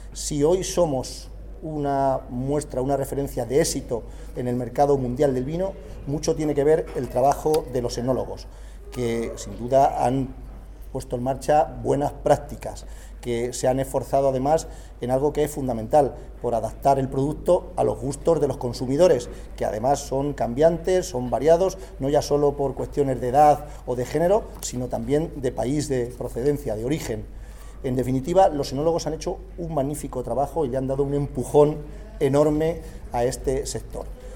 José Manuel Caballero ha valorado positivamente, desde el Congreso de Enólogos de Castilla-La Mancha, que en la región ya se han reestructurado 40.500 hectáreas de viñedo desde 2018 y, para ello, se han destinado 146 millones de euros, “que muestran el compromiso del Gobierno regional con el sector”.